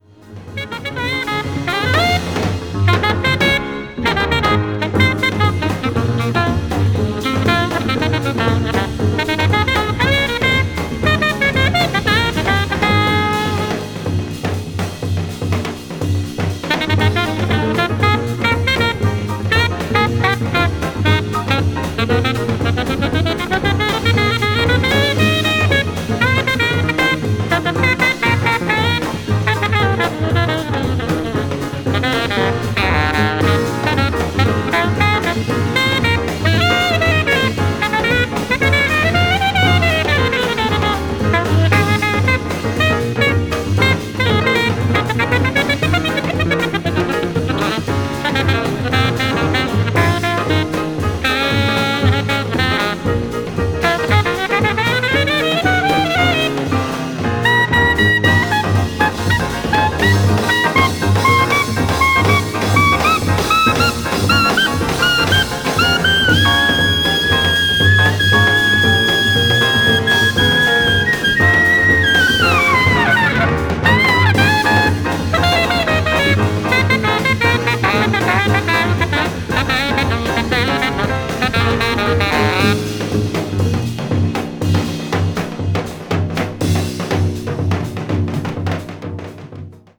contemporary jazz   ethnic jazz   post bop